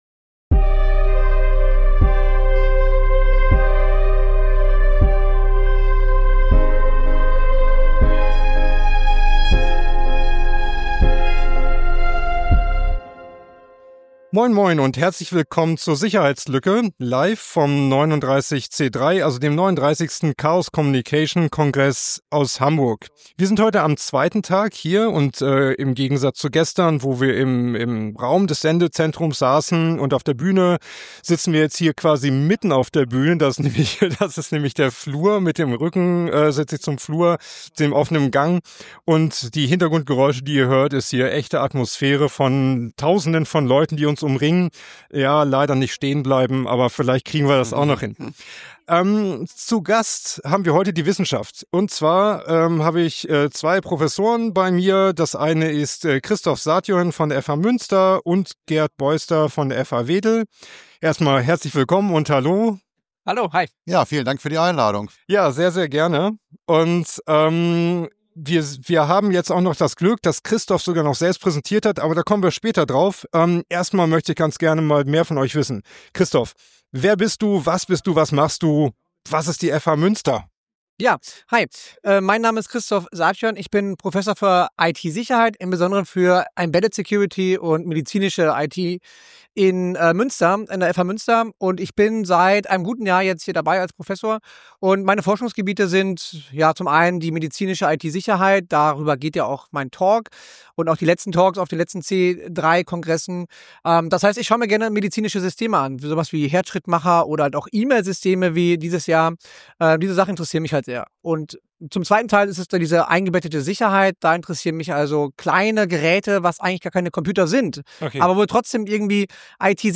Wir senden in diesem Jahr jeden Tag live vom 39C3, dem 39. Chaos Communication Congress in Hamburg.